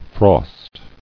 [frost]